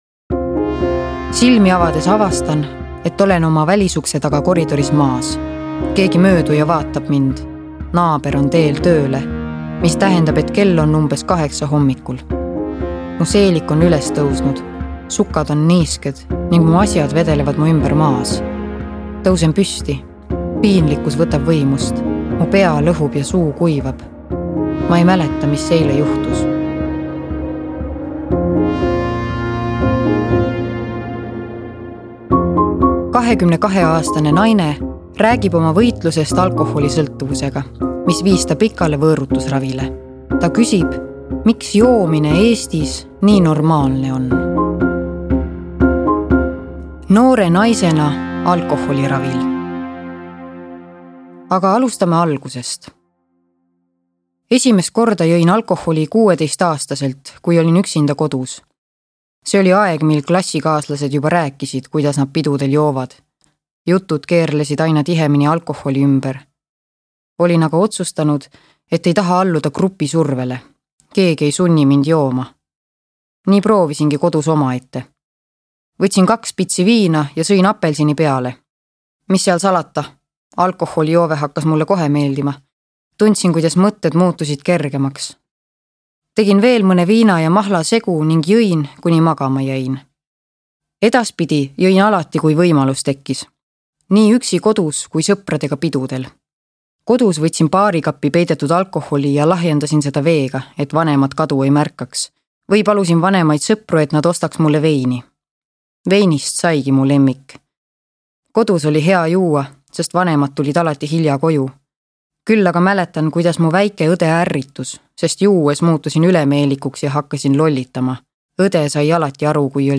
22-aastane naine räägib oma võitlusest alkoholisõltuvusega, mis viis ta pikale võõrutusravile. Ta küsib, miks joomine Eestis nii normaalne on.